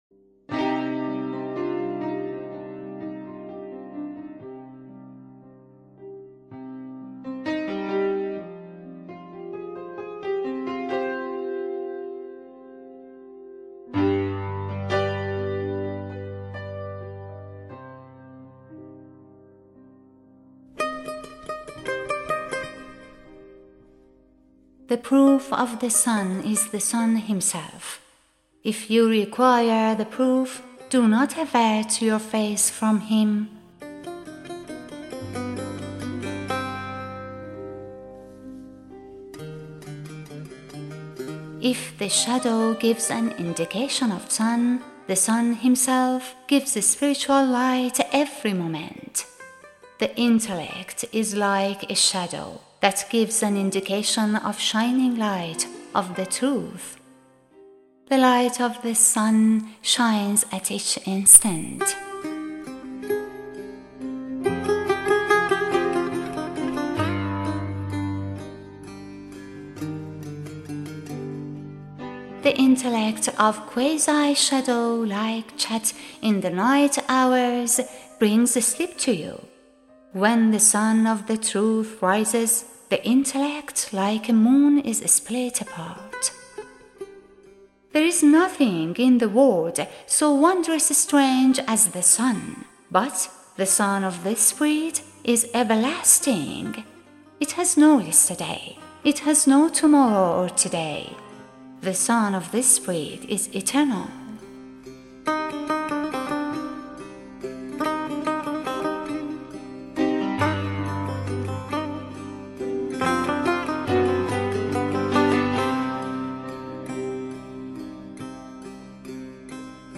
Narrator and Producer